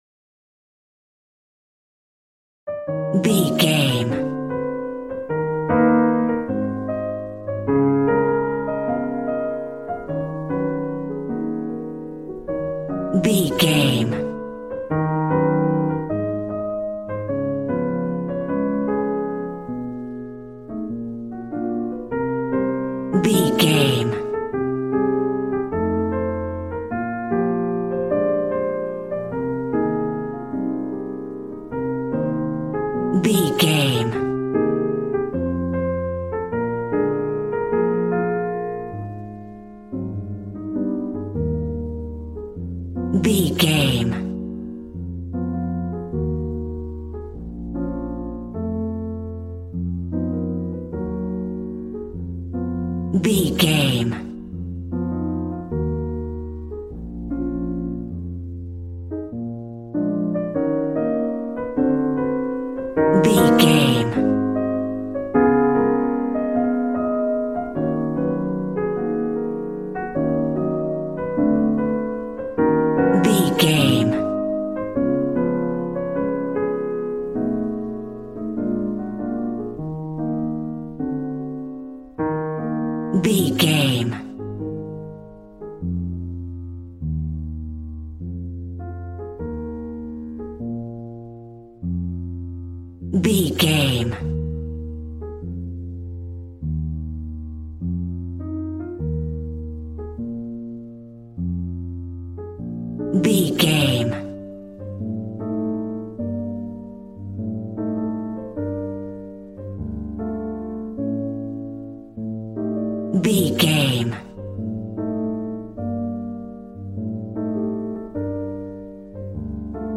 Smooth jazz piano mixed with jazz bass and cool jazz drums.,
Aeolian/Minor
E♭
piano
drums